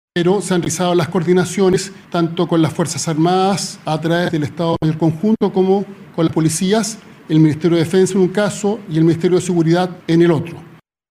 Así lo mencionó el ministro del Interior, Álvaro Elizalde, quien detalló el monitoreo fronterizo y la coordinación vigente.